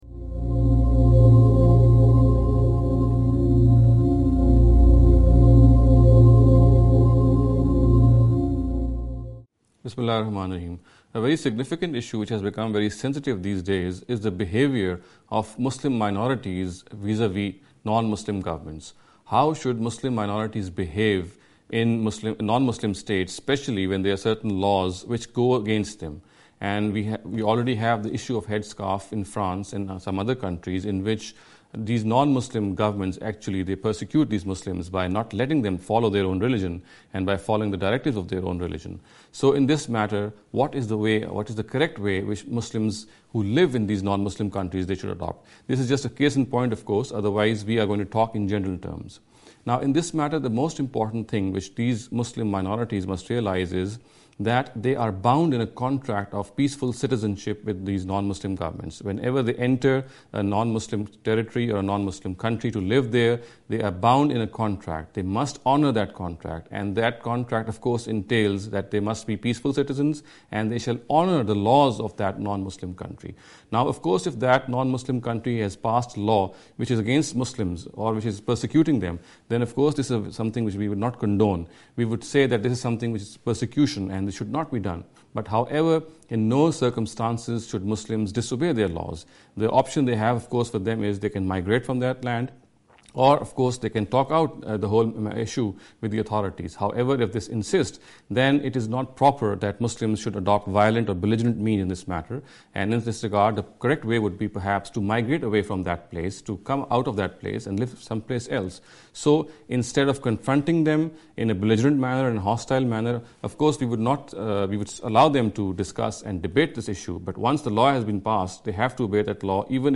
This lecture series will deal with some misconception regarding the Islam and Non-Muslims.